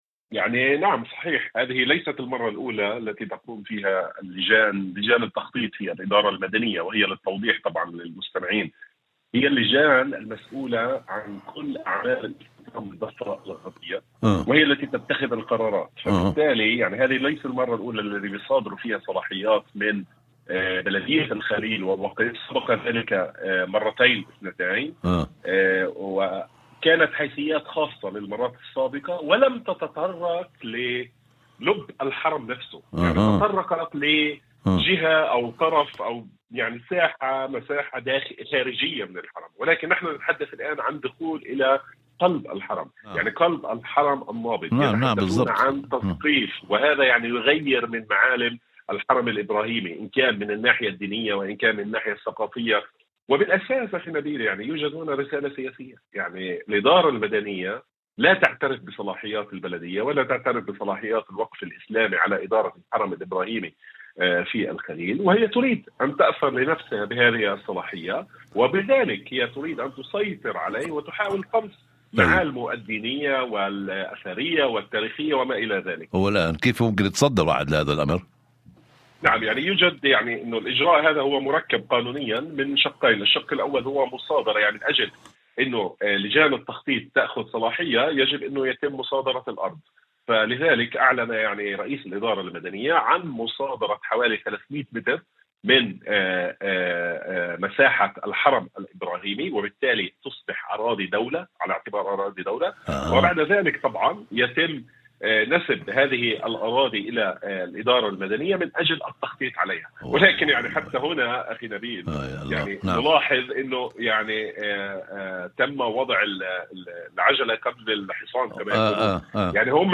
وفي مداخلة هاتفية ضمن برنامج "أول خبر"